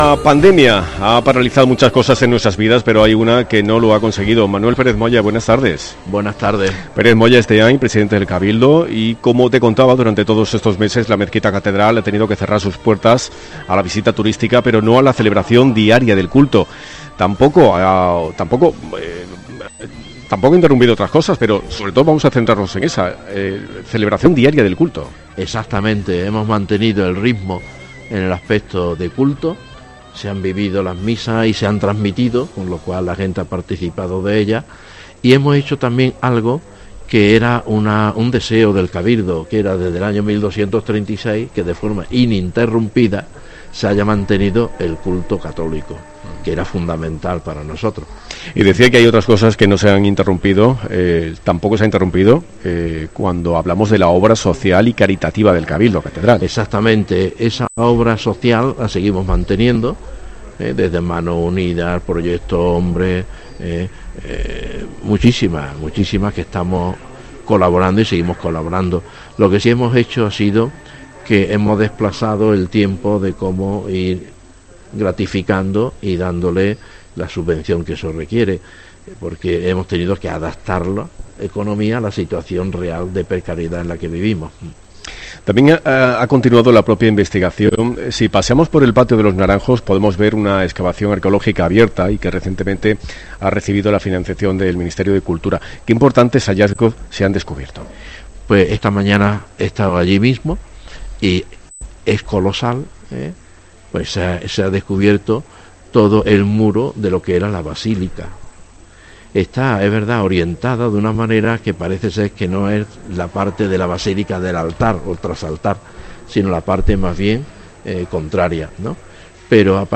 Escucha la entrevista completa al Deán Presidente del Cabildo Catedral